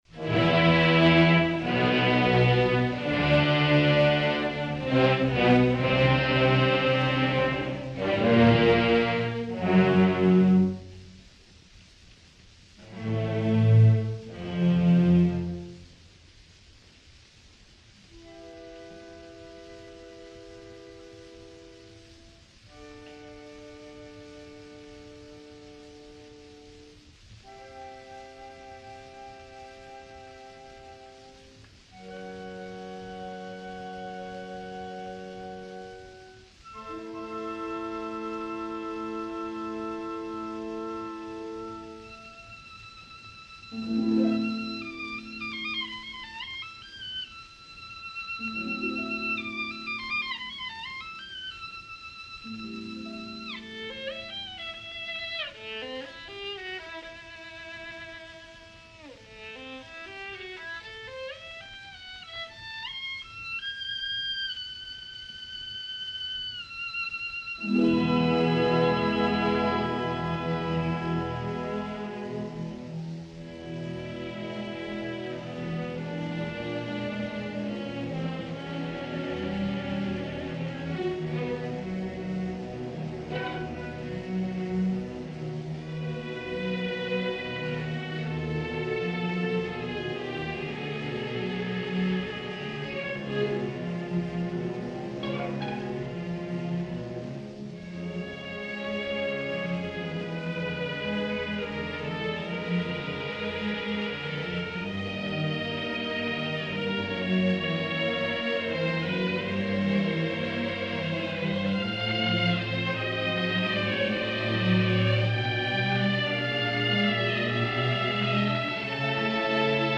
Nikolai Rimsky-Korsakov created his symphonic suite Sheherazade in 1888 and used less well-known stories for his source: Sindbad, The Kalendar Prince, The Prince and The Princess, and the Festival at Baghdad.
In the first movement, we could read the two contrasting themes as Shahryar (Heavy brass) and Scheherazade (solo violin over brass).
This 1927 recording has Philippe Gaubert leading the Orchestre de la Société des Concerts du Conservatoire.
Performed by
Philippe Gaubert
Orchestre de la Société des Concerts du Conservatoire